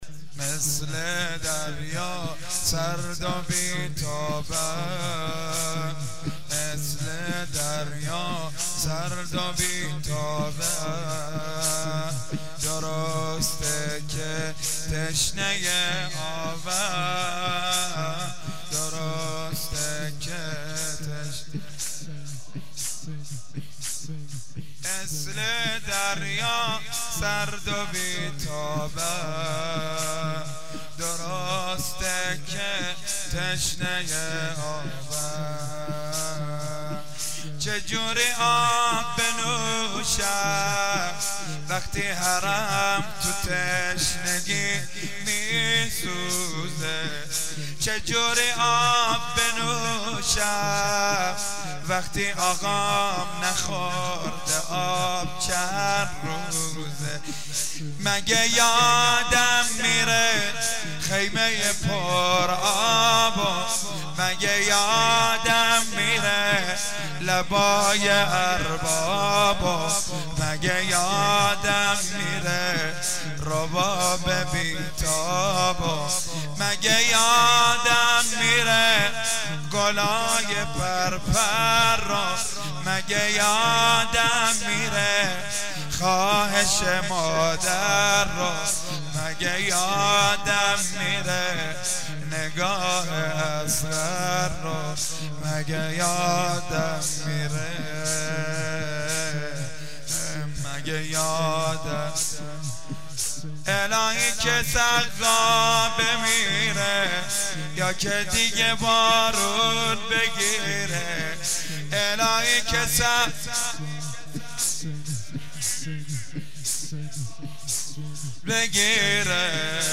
شب نهم محرم الحرام 1393
زمینه.mp3